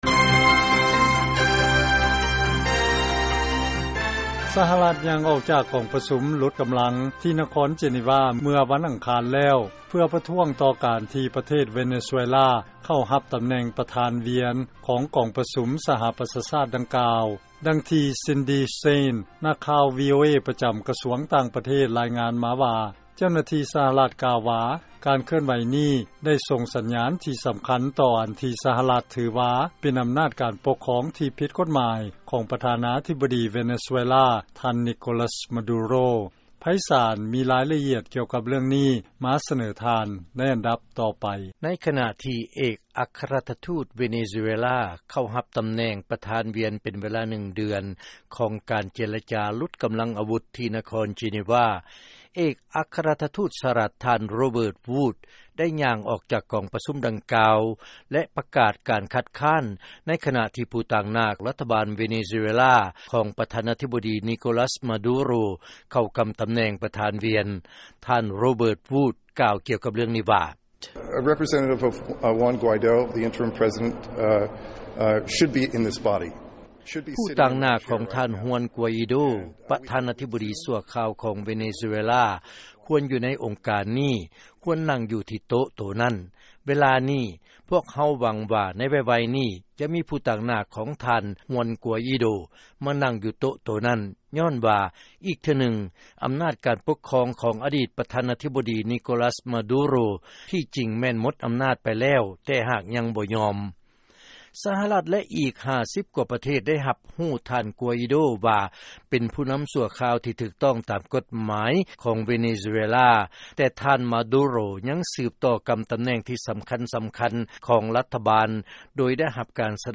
ເຊີນຟັງລາຍງານ ສຫລ ຄັດຄ້ານ ຕໍ່ການເຂົ້າກຳ ປະທານວຽນ ຂອງ ເວເນຊູເອລາ